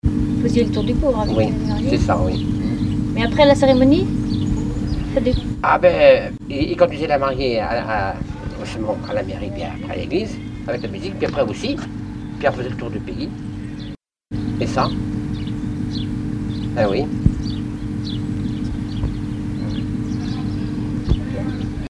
chanteur(s), chant, chanson, chansonnette ; musique, ensemble musical ; Instrument(s) de musique ;
Catégorie Témoignage